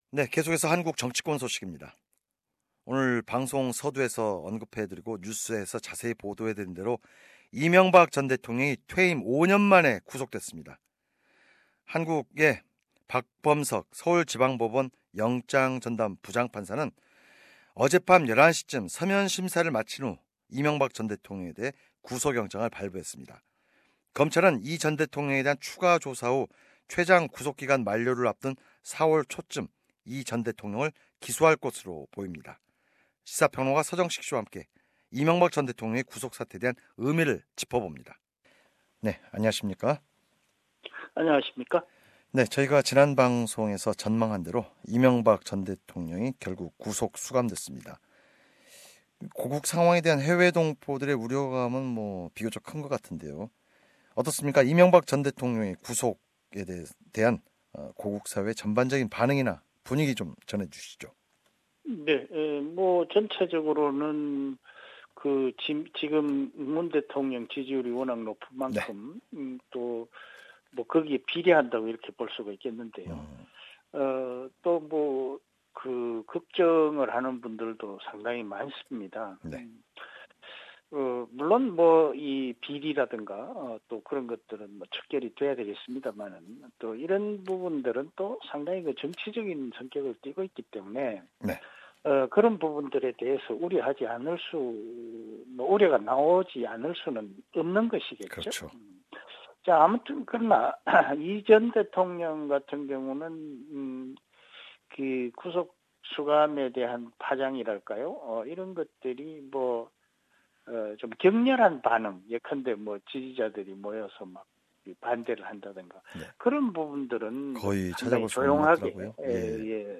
audio news